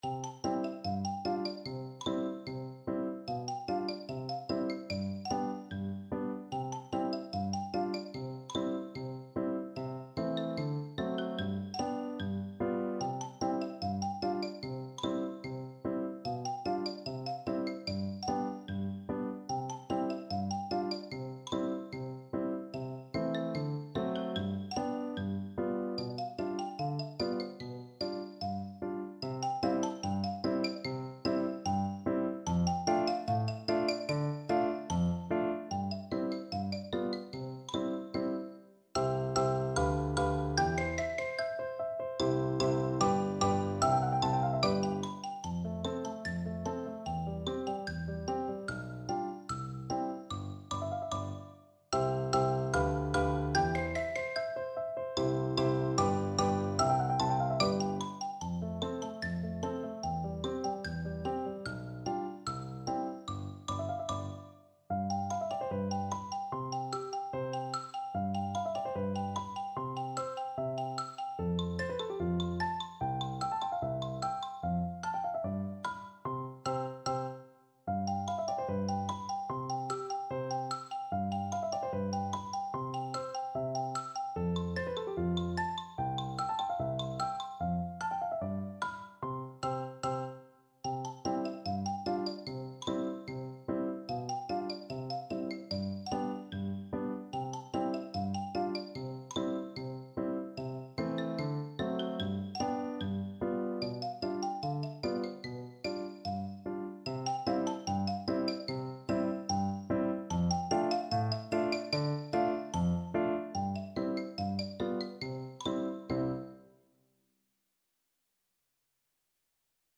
Free Sheet music for Orchestral Percussion
Xylophone
Allegretto = 74
Classical (View more Classical Percussion Music)